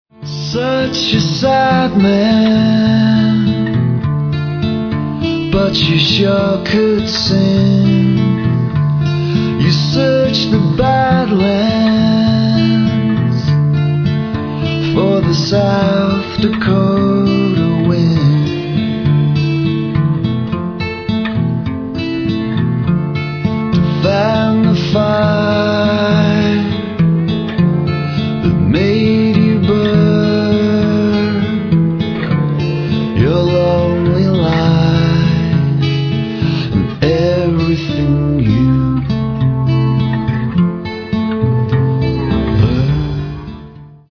Vocals, Acoustic Guitar
Bass, Vocals, Accordion, Harmonium
Electric Guitar, Vocals, Pedal Steel
Drums, Vocals, Percussion, Tack Piano